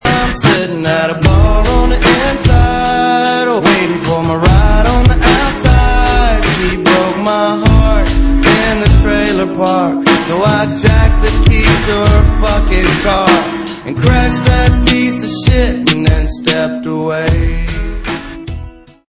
Tags: ringtones tones cell phone music melody country songs